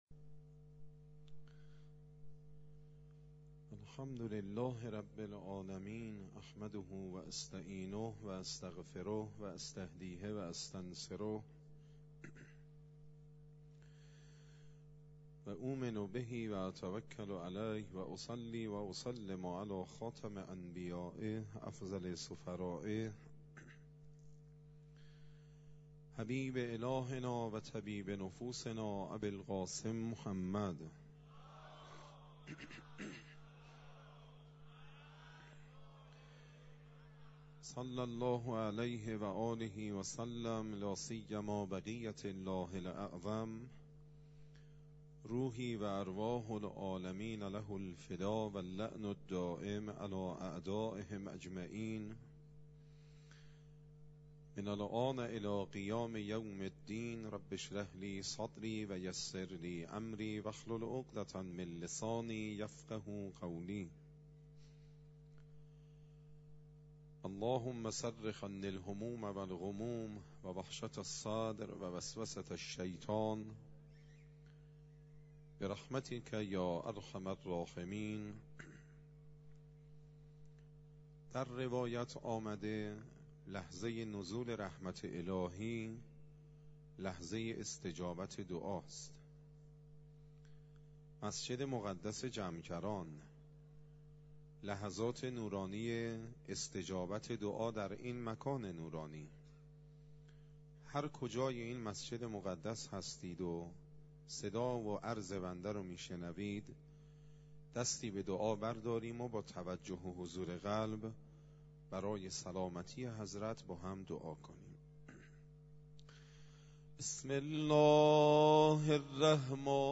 مسجد جمکران
سخنرانی